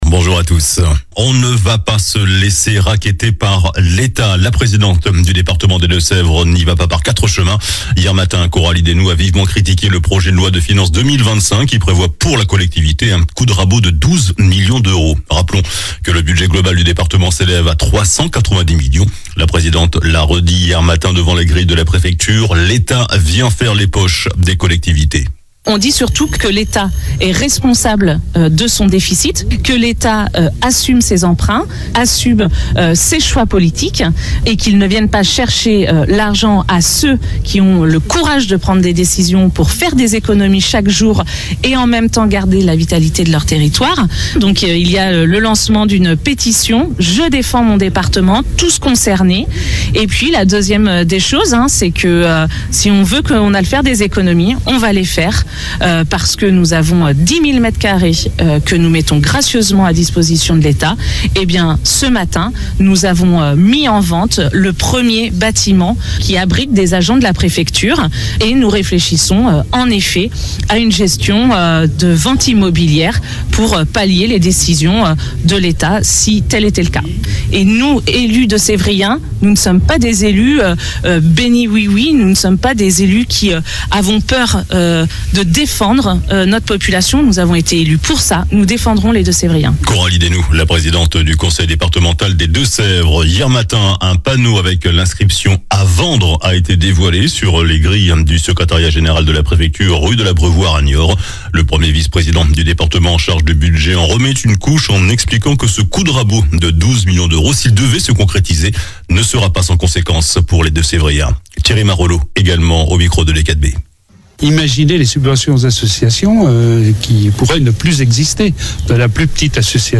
JOURNAL DU SAMEDI 26 OCTOBRE